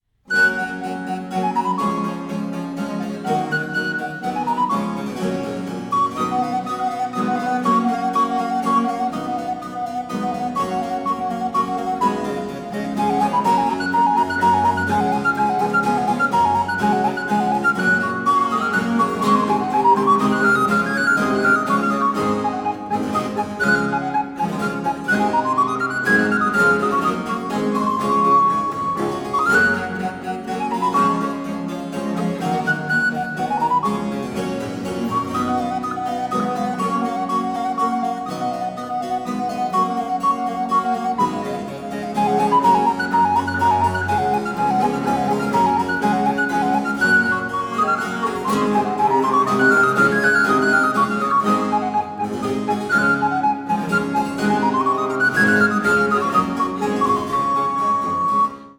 recorder, flute
violin
vielle
viola da gamba
cello
harpsichord
guitar
theorbo